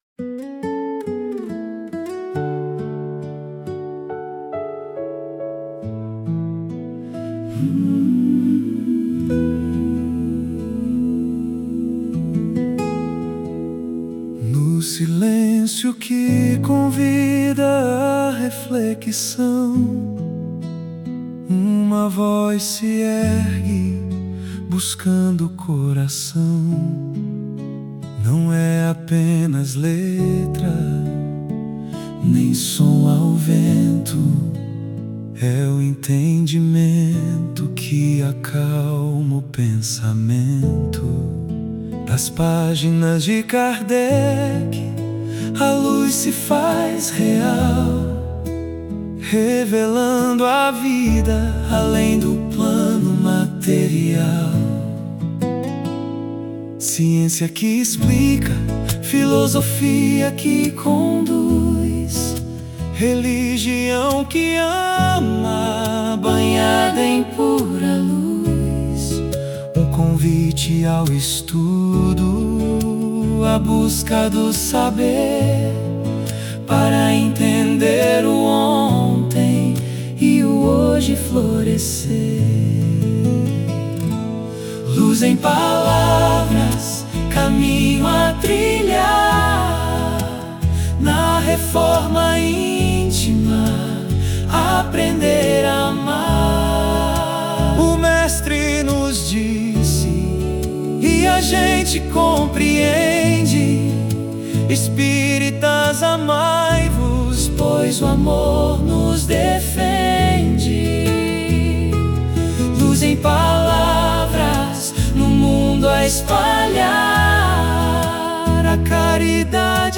✦ Produzido com Suno AI
Letra completa da música "Luz em Palavras" — composição espírita com viola caipira e piano, ideal para centros e grupos de estudo.